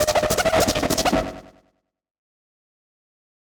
フリー効果音：スクラッチ
DJっぽいディスクを回す音（スクラッチ音）の効果音です！
scratch.mp3